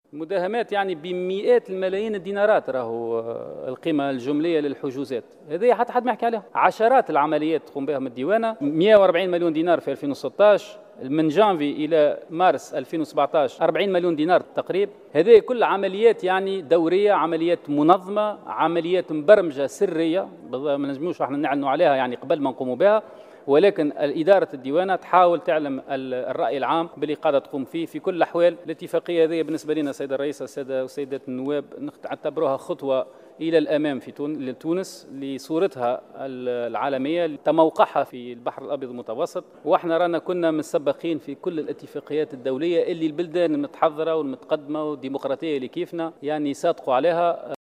وأضاف في تعقيبه على تدخلات النواب ،خلال جلسة عامة إلتأمت الثلاثاء بباردو، للنظر فى مشروع القانون الأساسي المتعلق بالموافقة على إنضمام تونس للإتفاقية الدولية حول تبسيط ومواءمة الأنظمة الديوانية (اتفاقية كيوطو)، أن الحملة جرت بعد سلسلة مطولة من الاجتماعات التي تم خلالها متابعة ملفات وشبهات الفساد لأسماء ضالعة ومورطة في ملفات فساد، مفندا ما اعتبره" تشكيكا في دواعي الحملة وتوقيتها".